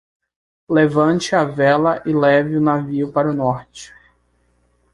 Pronúnciase como (IPA)
/ˈvɛ.lɐ/